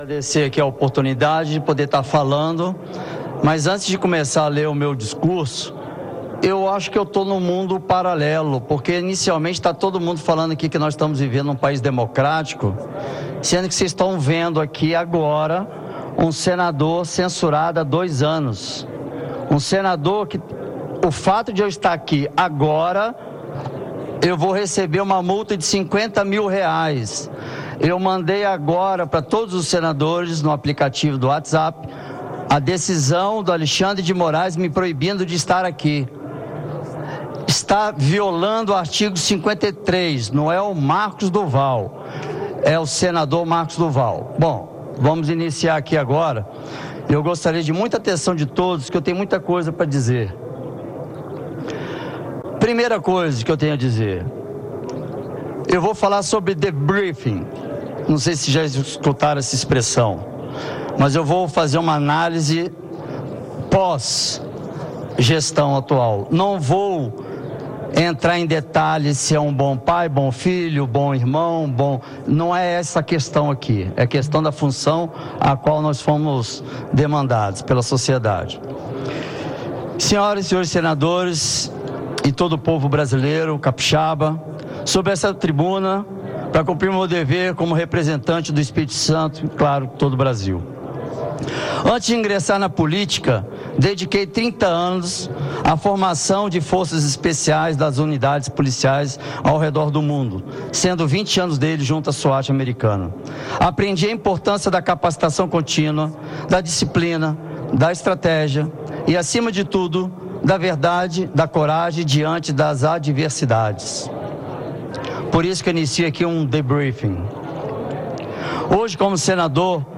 Discurso do candidato Marcos do Val
O senador Marcos do Val (Podemos-ES) apresenta suas propostas para presidir o Senado em discurso na reunião preparatória deste sábado (1º).